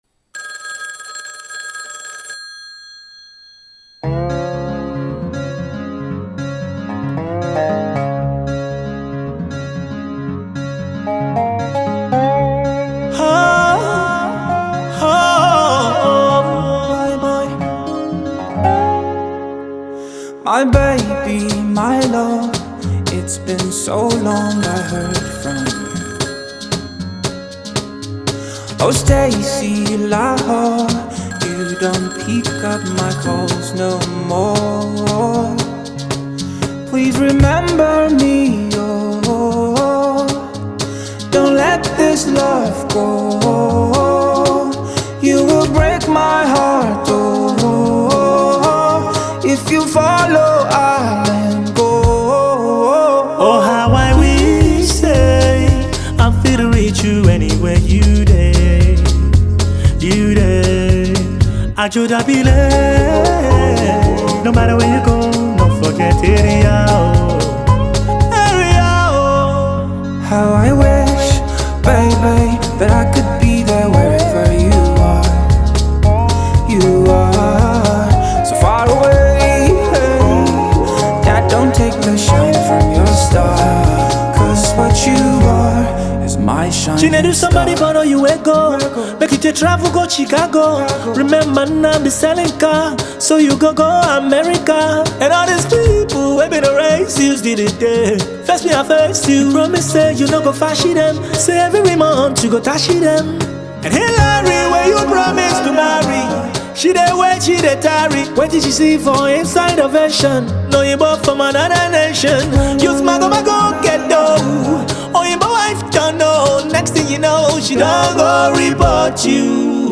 Veteran Afro & Hip-hop singer